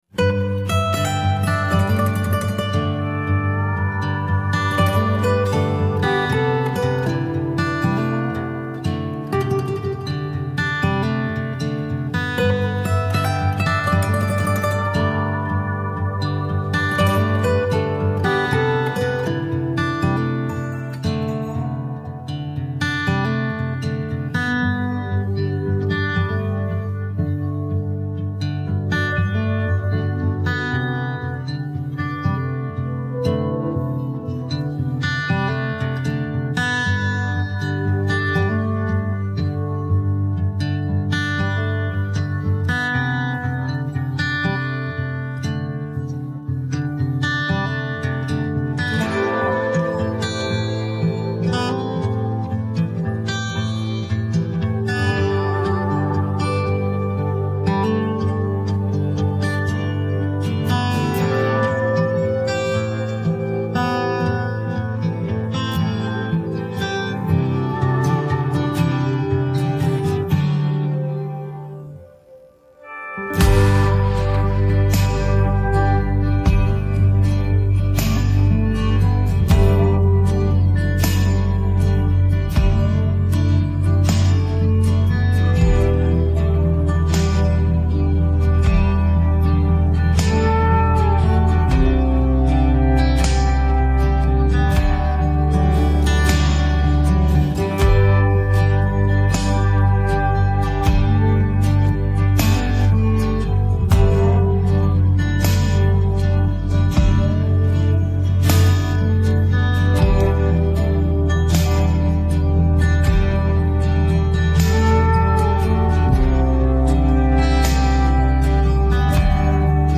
เพลงร้ย